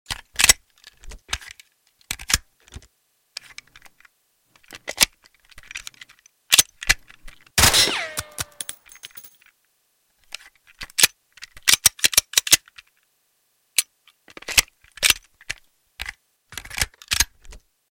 Glock 18 from Delta Force sound effects free download